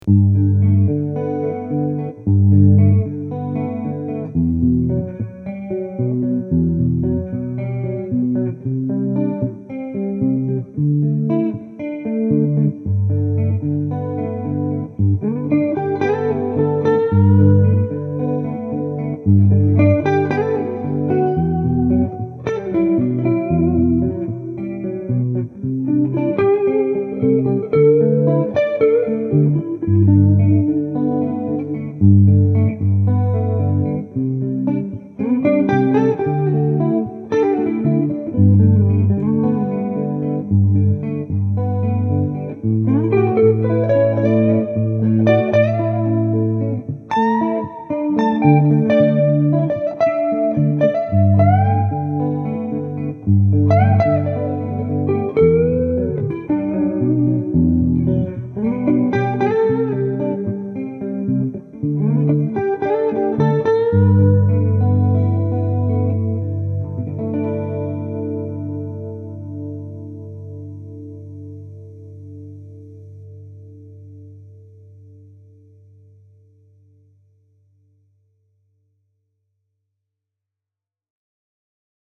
Middle position, clean; both rhythm and lead
🙂 I added a touch of reverb and chorus to get that “Every Breath You Take” vibe.
For the clean clips, I used a ’65 Twin Reverb model, and for the crunchy clip, I used a ’59 Bassman.